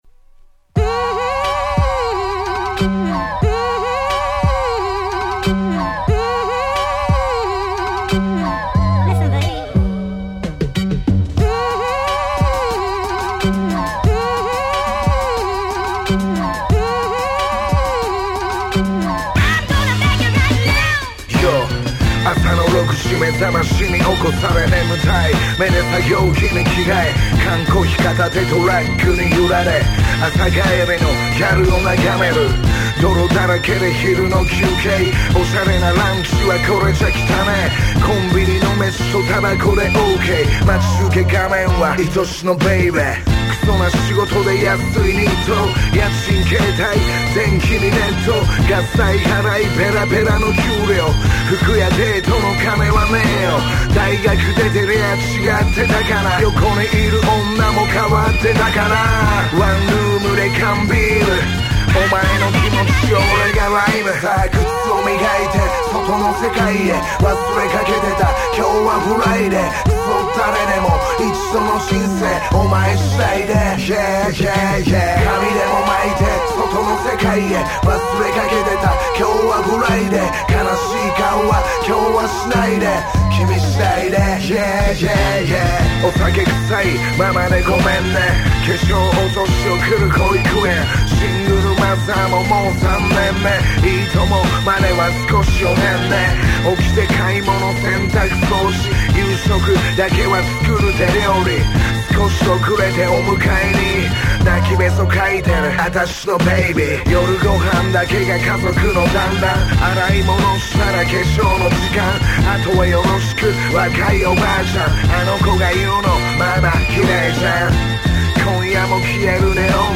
13' Japanese Hip Hop Super Classics !!